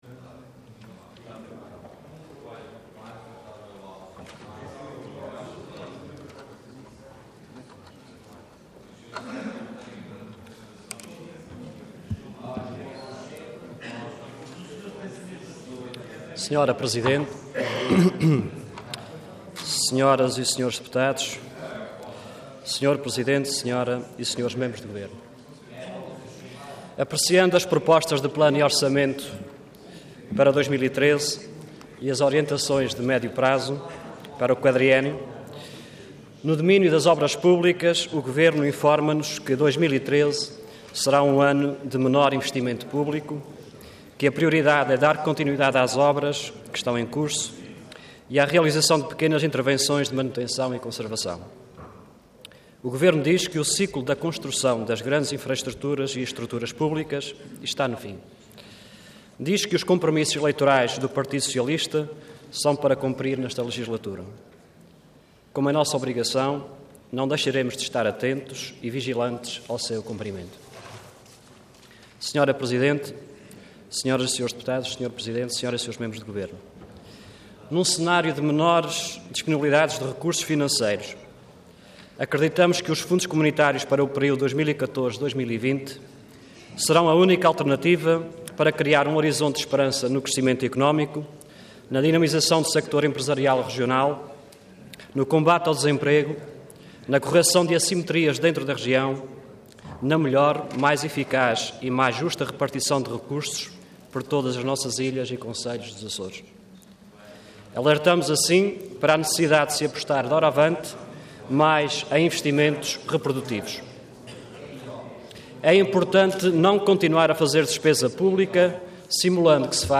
Intervenção Intervenção de Tribuna Orador Cláudio Lopes Cargo Deputado Entidade PSD